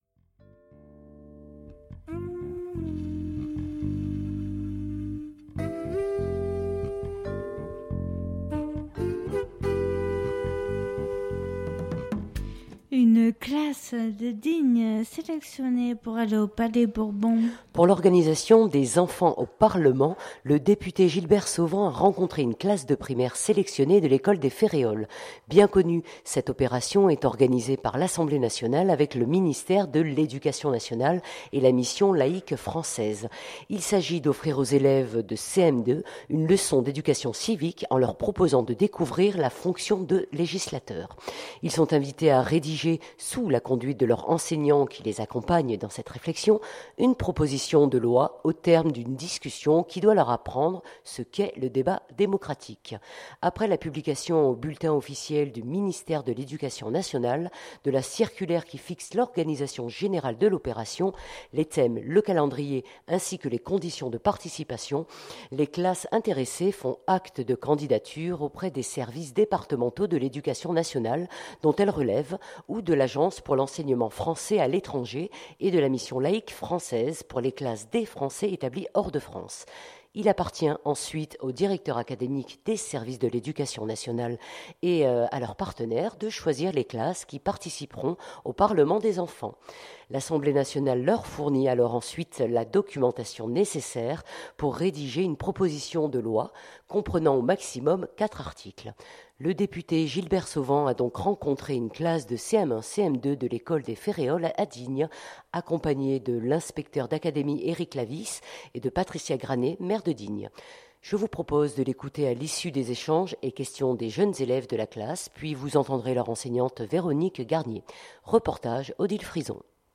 Je vous propose de l’écouter à l’issue des échanges et questions des jeunes élèves de la classe
Reportage